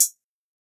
UHH_ElectroHatB_Hit-27.wav